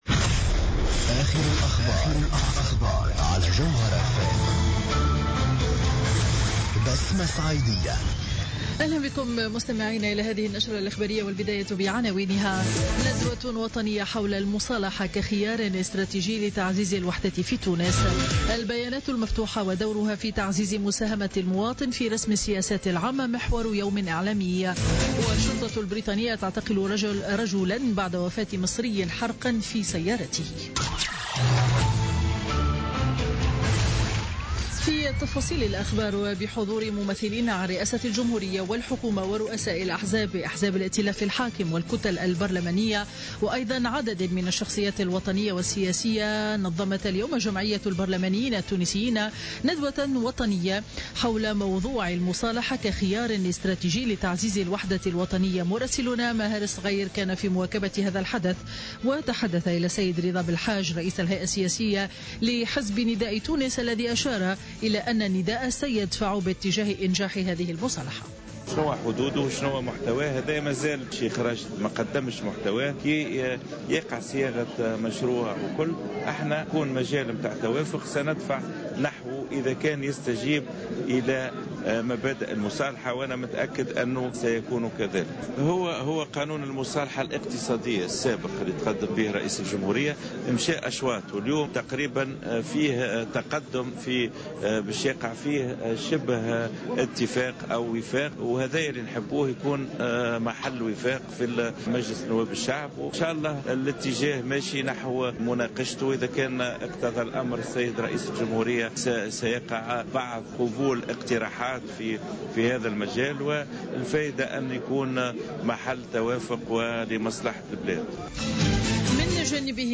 نشرة أخبار منتصف النهار ليوم الأربعاء 27 أفريل 2016